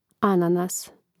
ȁnanas ananas